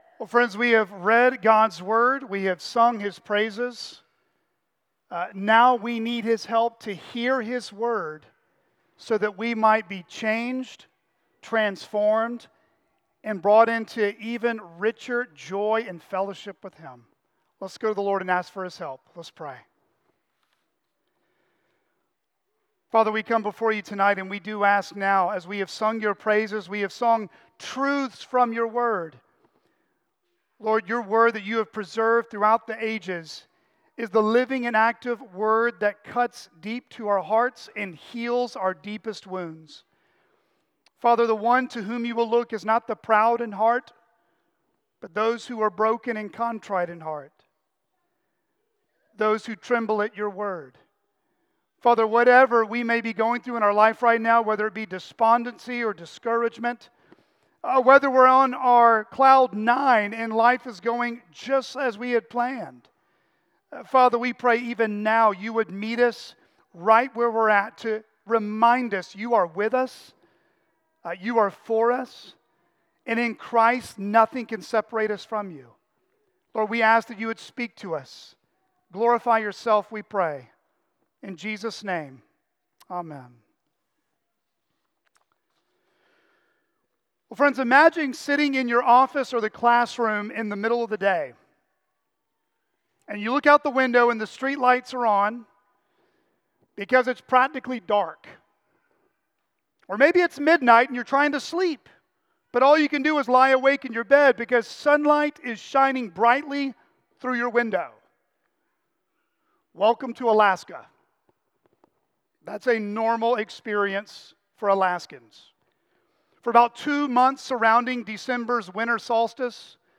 CCBC Sermons Isaiah 9:1-2 Dec 25 2024 | 00:31:06 1x 00:00 / 00:31:06 Subscribe Share Apple Podcasts Spotify Overcast RSS Feed Share Link Embed